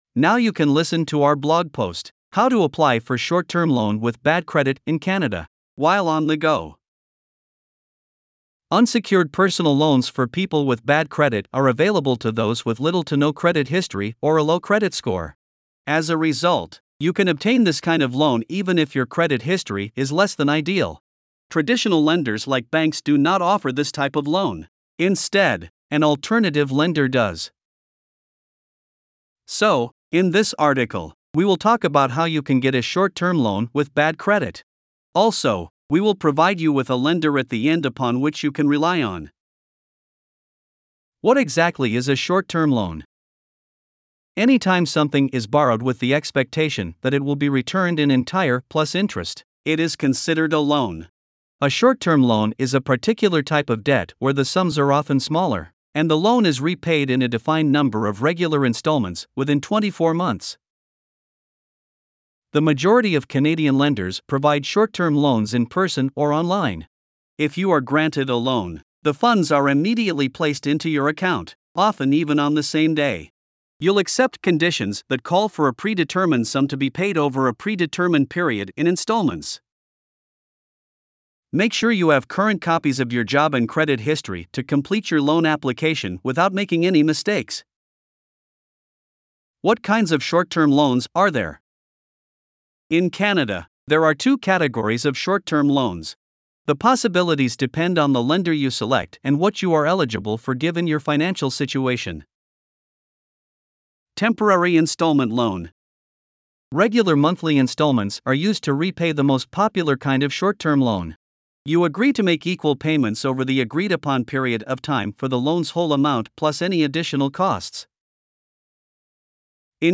Voiceovers-Voices-by-Listnr_23.mp3